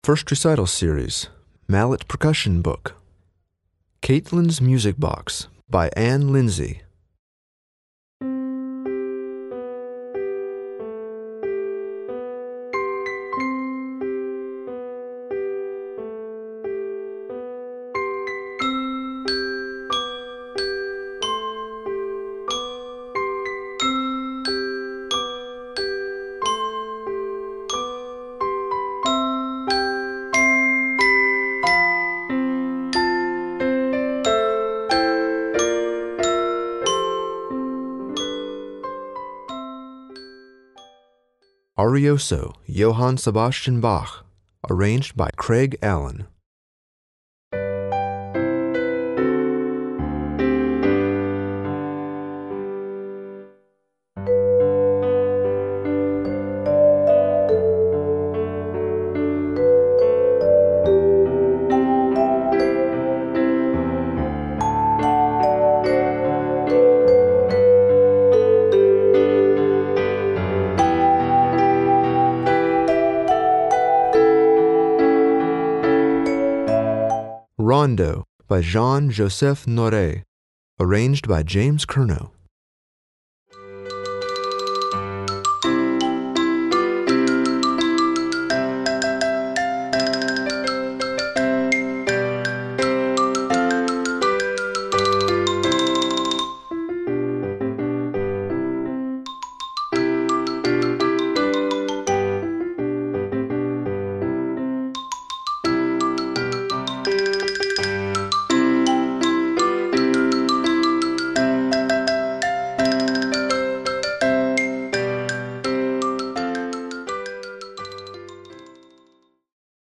Voicing: Mallet Collection